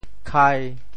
「开」字用潮州話怎麼說？
khai1.mp3